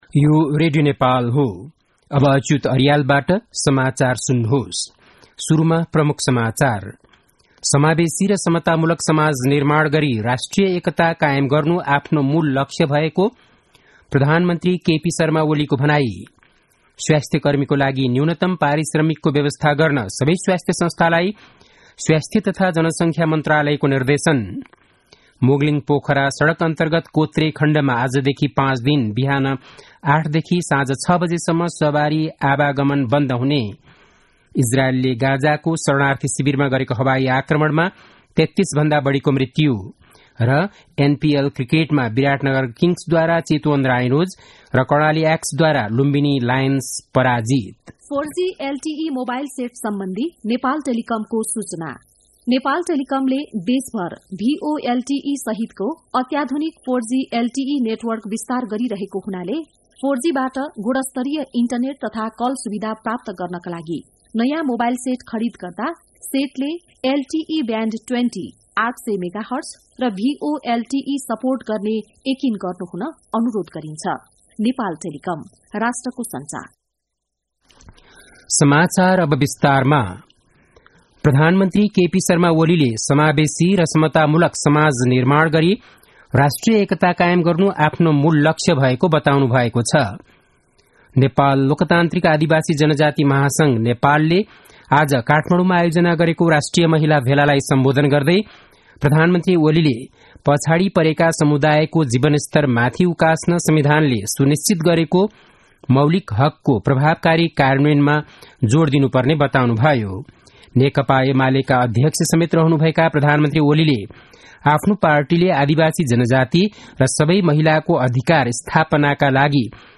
बेलुकी ७ बजेको नेपाली समाचार : २९ मंसिर , २०८१
7-pm-nepali-news-8-28.mp3